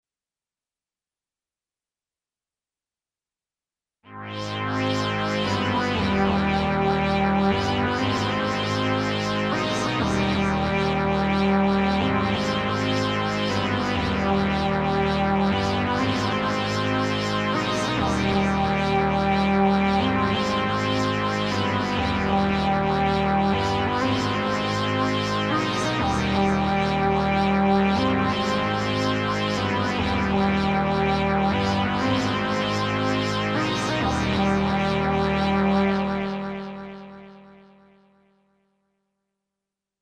I rimanenti tre campioni stanno a testimoniare proprio questo. con Pad morbidi e sognanti (XioSynth04.mp3), evocative percussioni sintetiche (XioSynth05.mp3) e classici synth bass  (XioSynth06.mp3) lo XioSynth riesce ad esprimersi benissimo in molte tipologie di sonorità sintetiche, con la morbidezza che ormai è marchio di fabbrica in casa Novation.